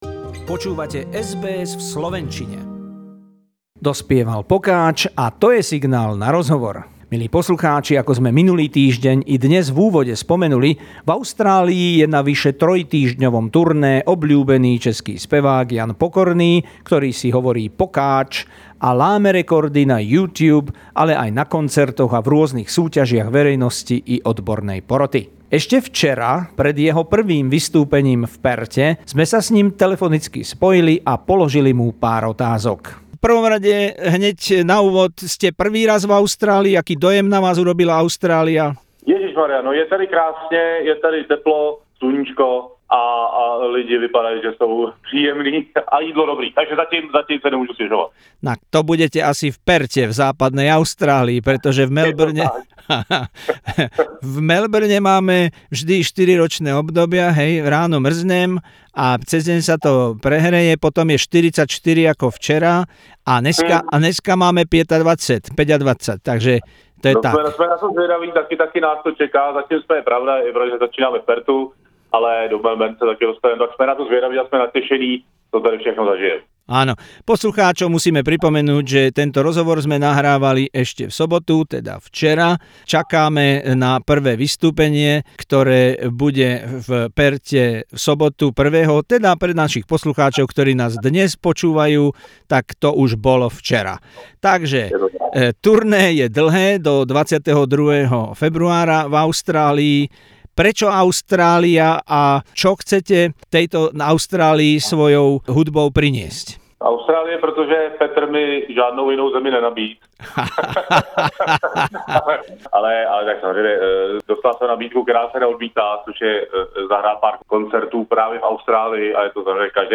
Interview with the famous Czech singer and musician Jan Pokorny - Pokac, during his first visit to Australia, dont forget to see his concert.